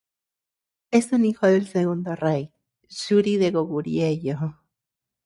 Pronounced as (IPA) /ˈrei/